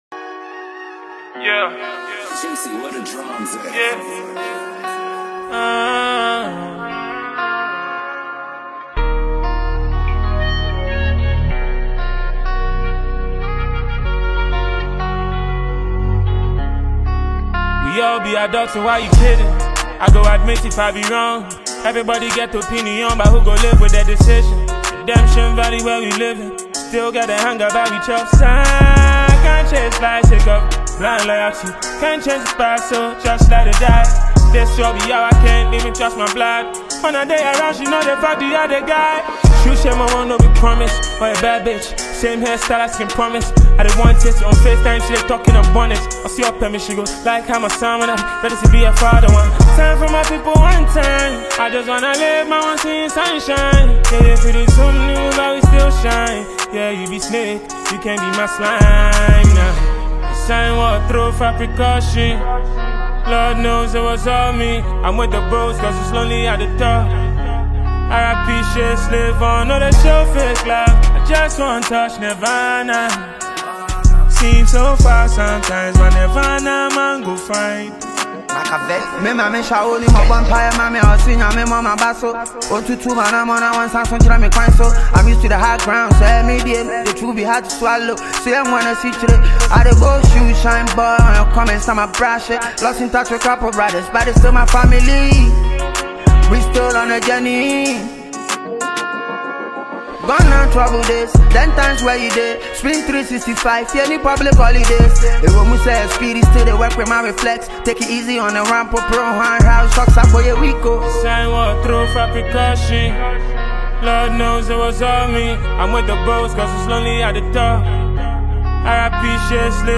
hip hop and afrobeat singer